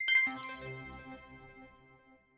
Button_8_Pack2.wav